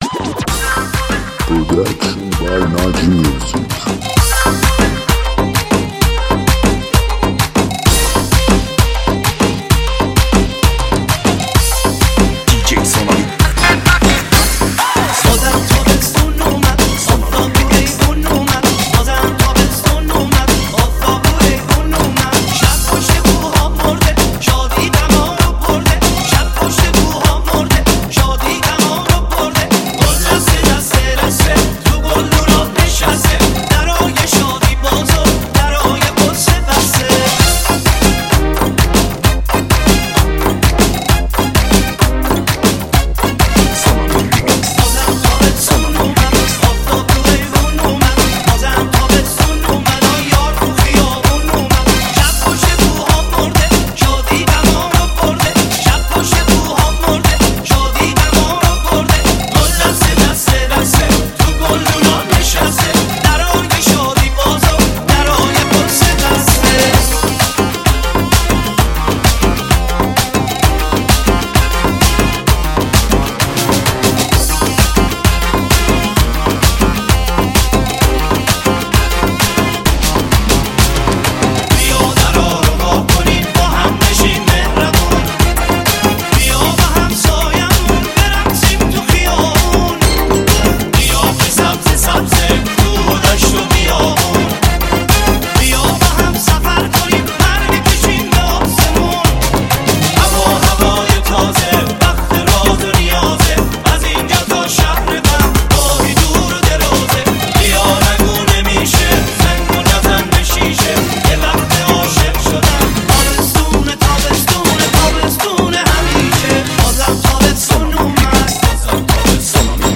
ریمیکس شاد رقصی